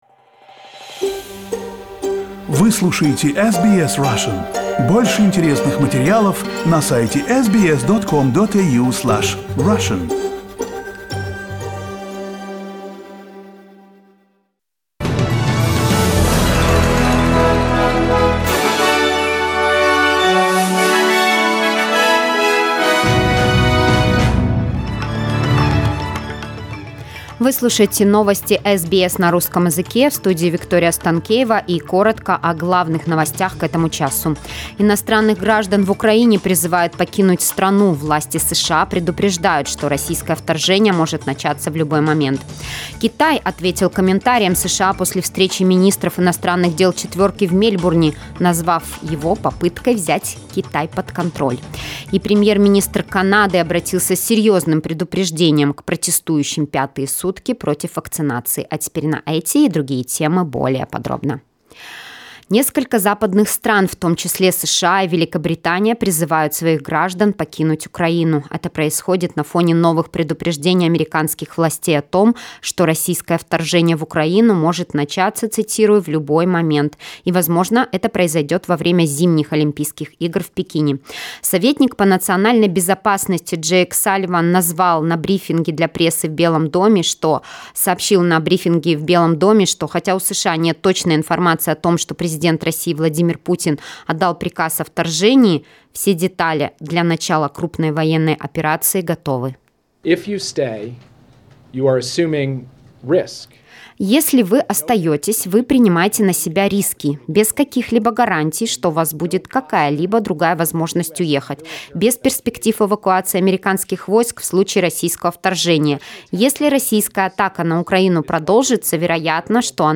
SBS news in Russian - 12.01